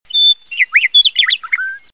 云雀鸟的叫声 鸟叫音效
【简介】： 云雀鸟的叫声、鸟叫音效